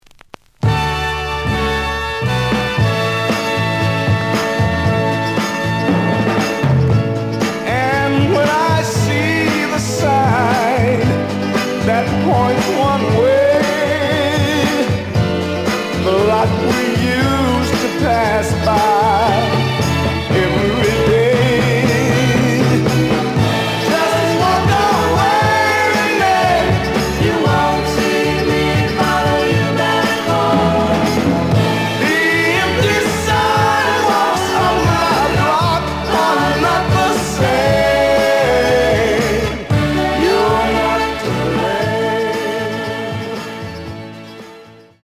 The audio sample is recorded from the actual item.
●Genre: Soul, 60's Soul
Some damage on both side labels. Plays good.)